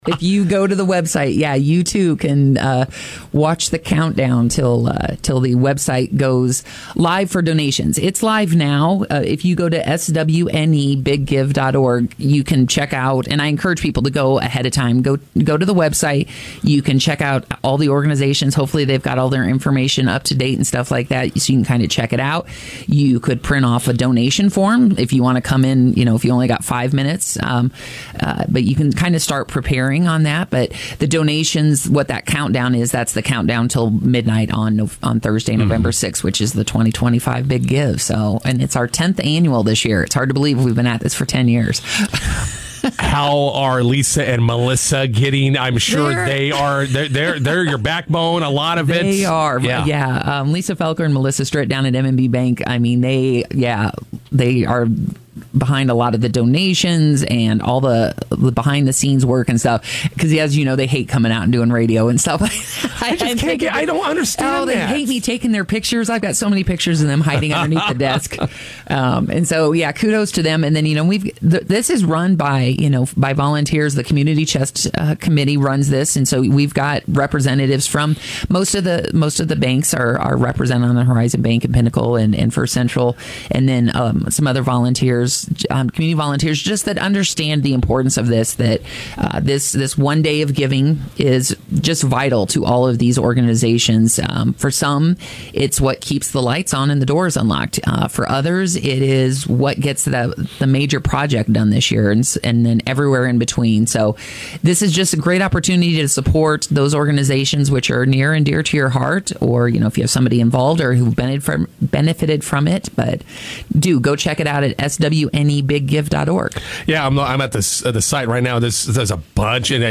INTERVIEW: Southwest Nebraska Big Give coming up tomorrow.